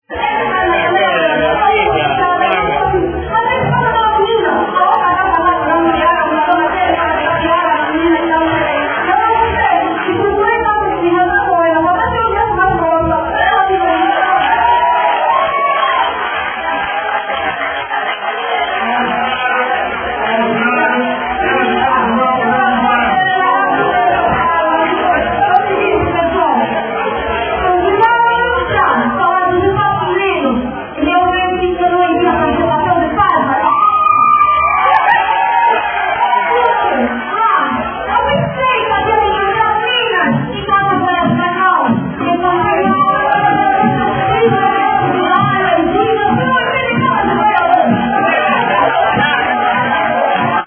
(nessa altura o o man do mic começou a bater um beat e não deixou ouvir nada, porra.
[Excerto mp3 8kbps]